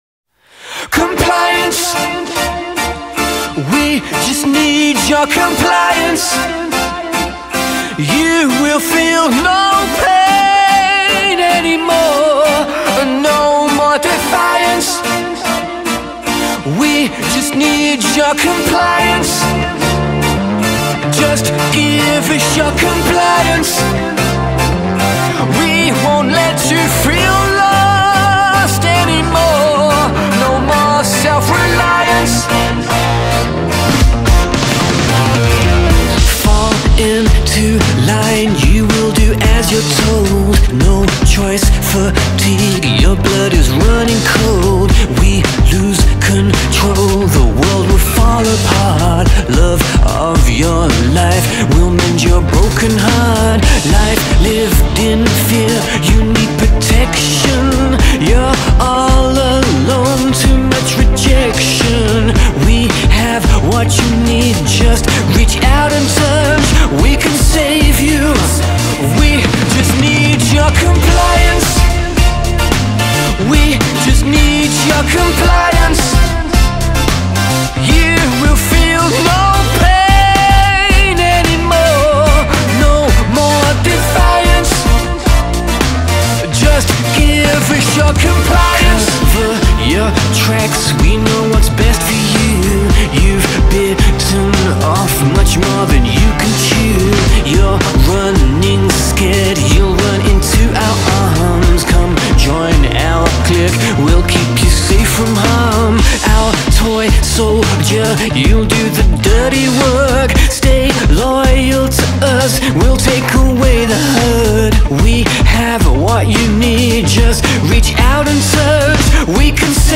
synth-rock